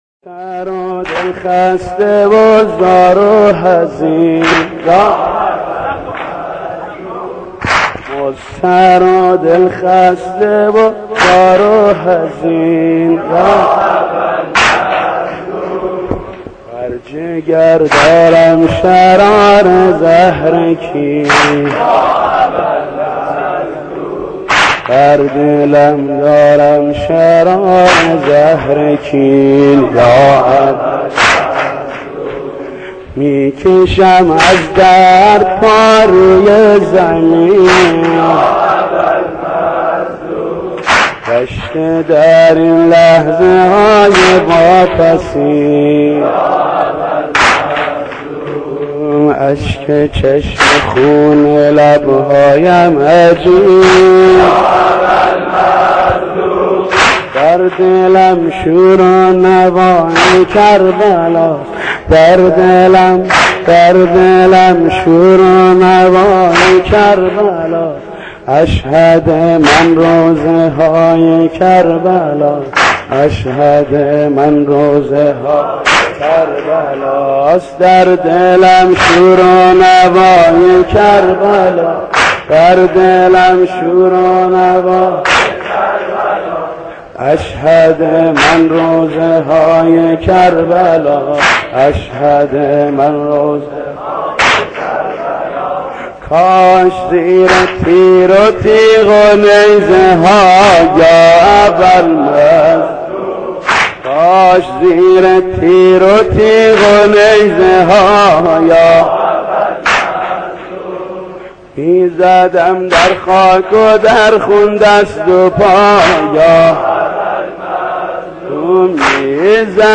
دانلود مداحی جدید